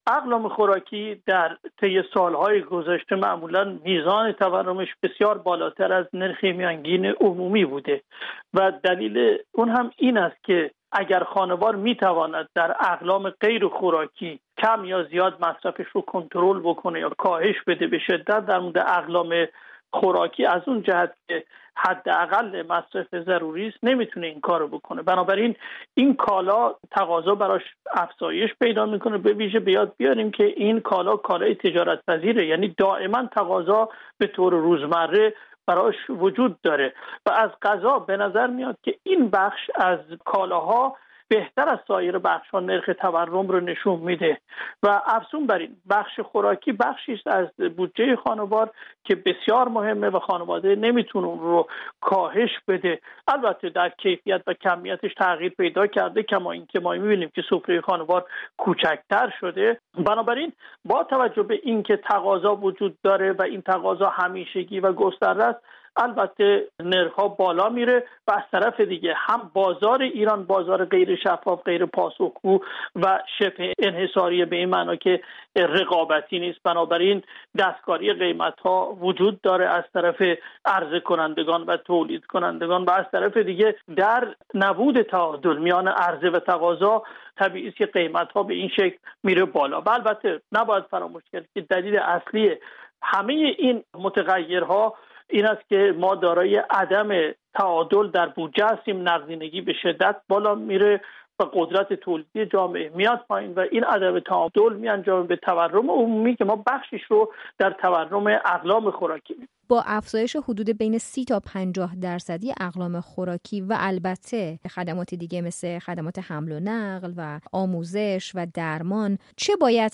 خبرگزاری ایلنا در گزارشی با اشاره به افزایش یک میلیون و ۱۷۰ هزار تومانی سبد خوراکی ها در دو ماه گذشته، نوشته که به این ترتیب بالا رفتن ۳۹ درصدی حقوق کارگران در سال جاری را بی حاصل دانسته است. گفت‌وگو